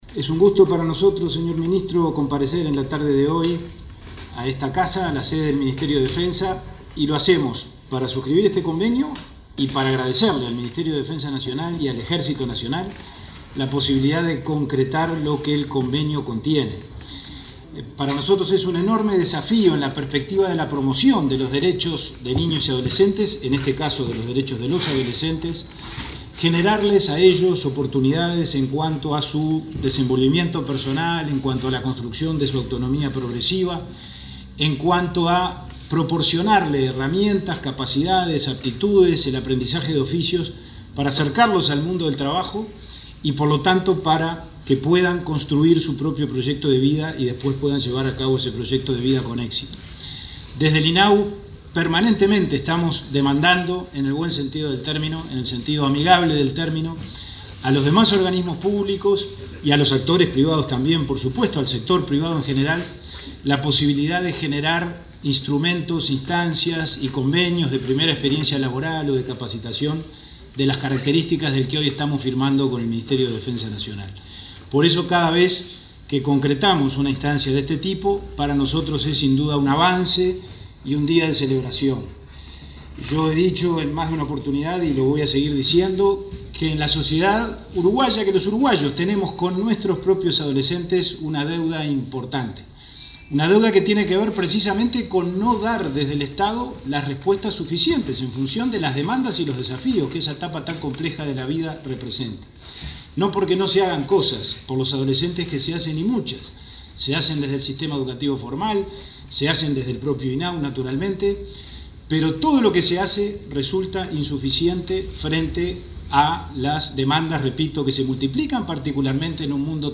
Conferencia de prensa por la firma de acuerdo entre el MDN y el INAU
Conferencia de prensa por la firma de acuerdo entre el MDN y el INAU 13/09/2021 Compartir Facebook X Copiar enlace WhatsApp LinkedIn El Ministerio de Defensa Nacional (MDN) y el Instituto del Niño y Adolescente del Uruguay (INAU) firmaron un convenio que les permitirá fomentar la coordinación y cooperación institucional. Participaron del evento el ministro de Defensa, Javier García; el comandante del Ejército, Gerardo Fregossi, y el presidente del INAU, Pablo Abdala.